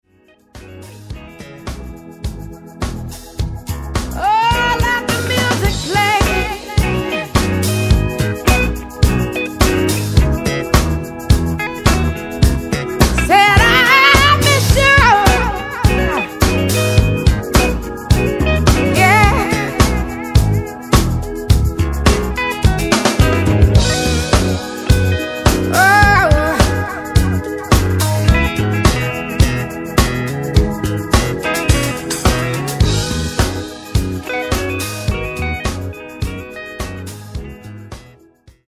往年のSOUL/FUNKクラシックを完璧に歌いこなす珠玉のカバー集を緊急リリース!